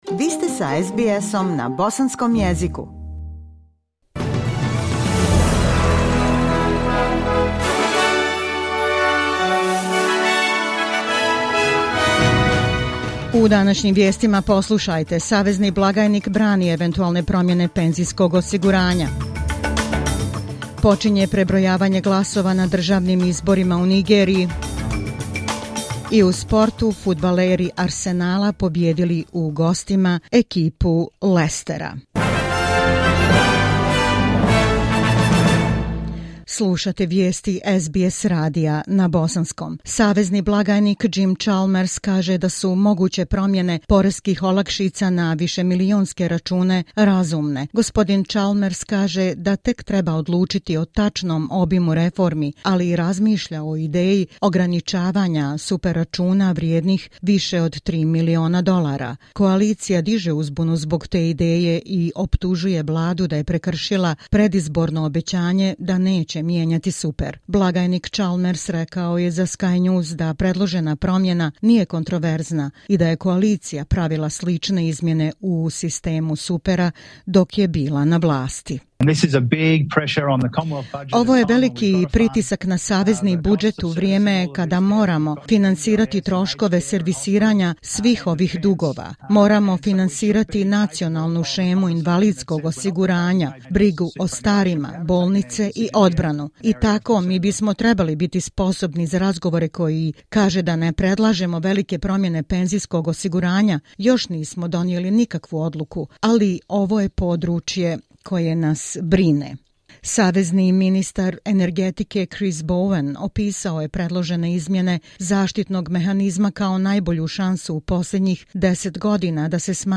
SBS radio news in the Bosnian language.